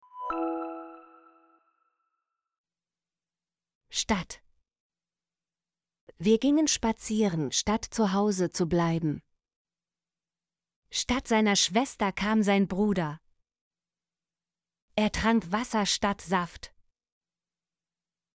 statt - Example Sentences & Pronunciation, German Frequency List